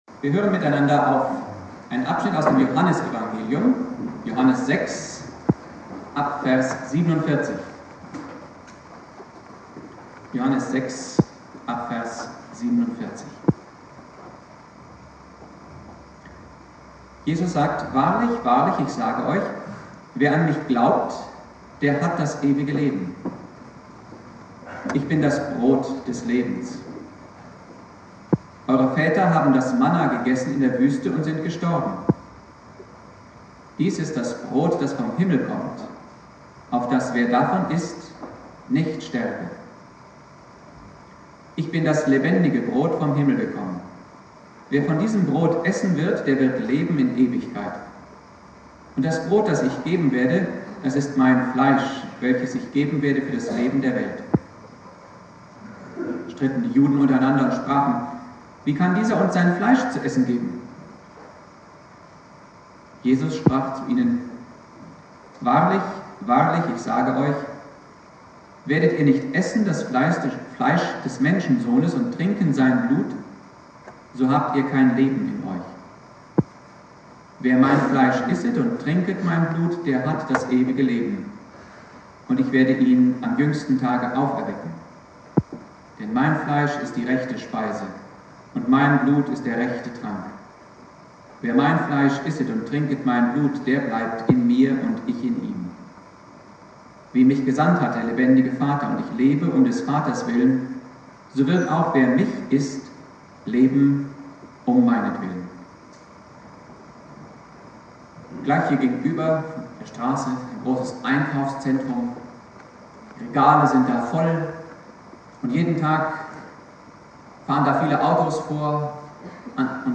Thema: "Brot des Lebens" (mit Außenmikro aufgenommen) Bibeltext